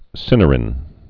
(sĭnər-ĭn)